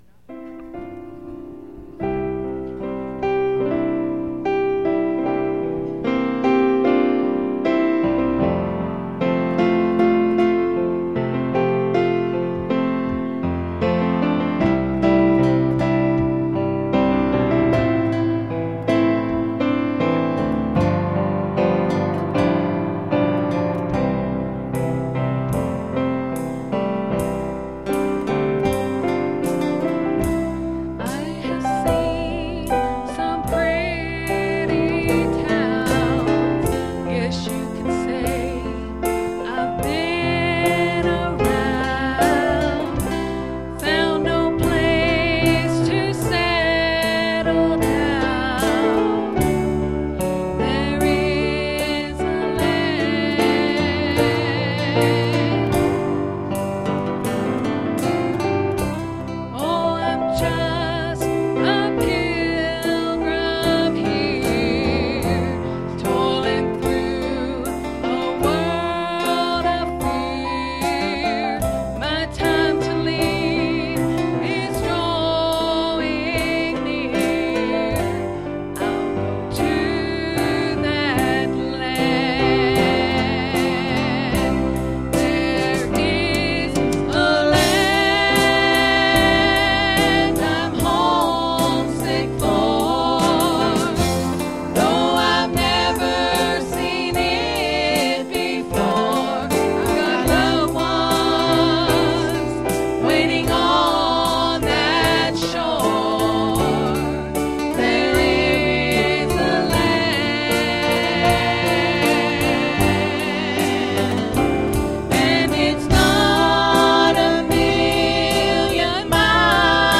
Special Singing
Service Type: Special Service